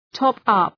Προφορά
top-up.mp3